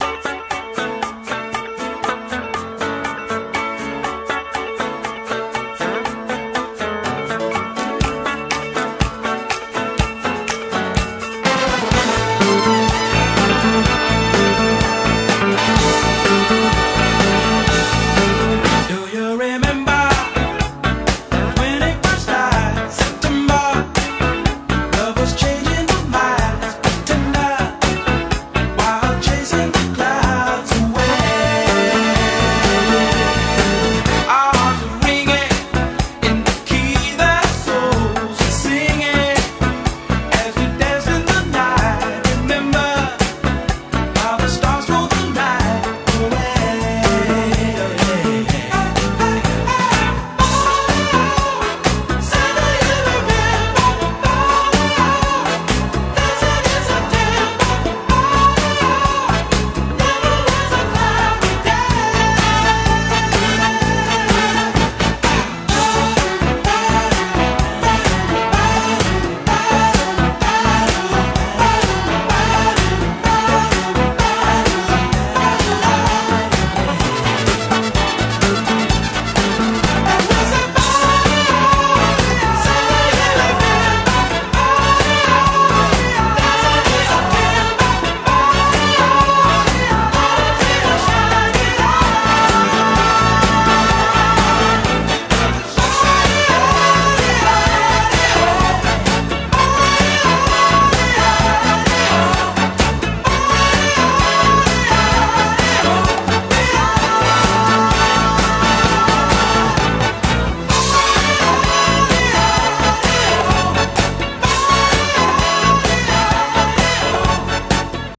BPM120-134